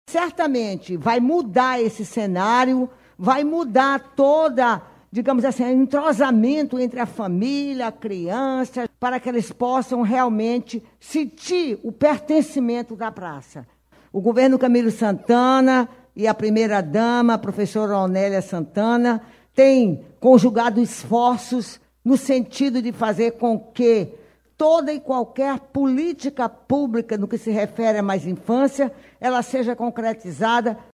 A secretária da SPS, Socorro França falou da importância da brinquedopraça para a integração entre as famílias e dos investimentos do Governo do Ceará na área social.